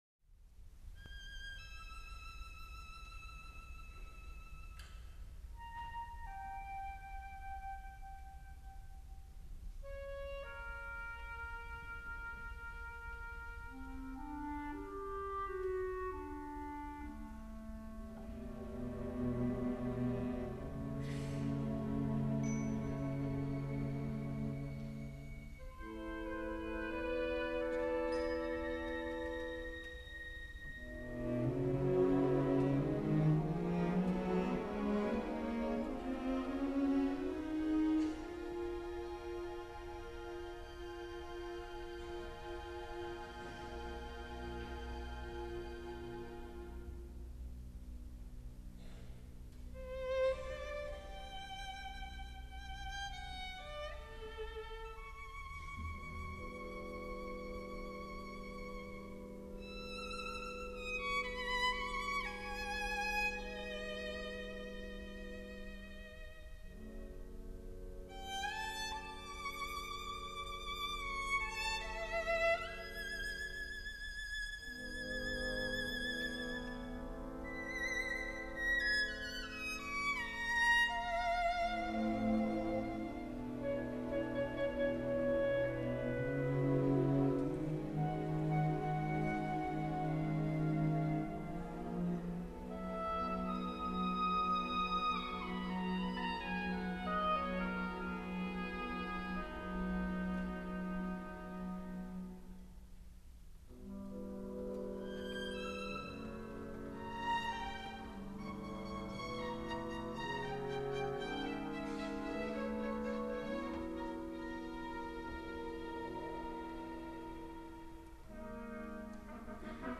for Chamber Orchestra (1987)